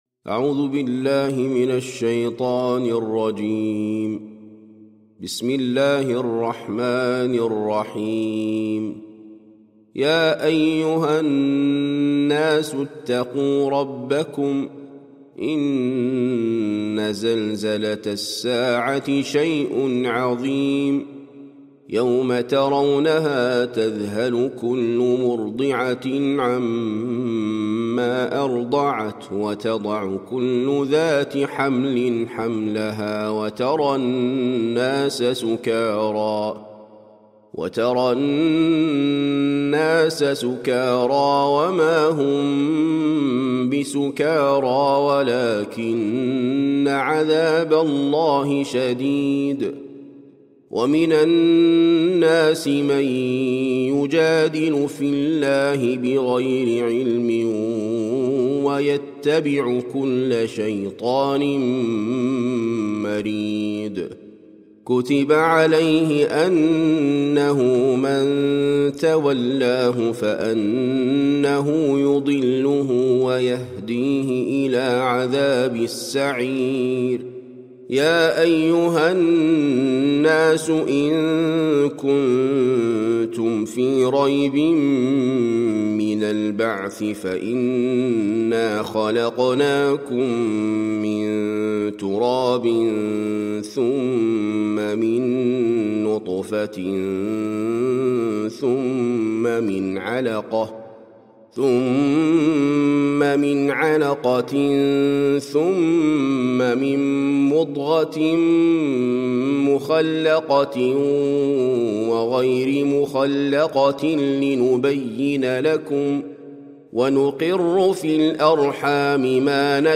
سورة الحج - المصحف المرتل (برواية حفص عن عاصم)
جودة عالية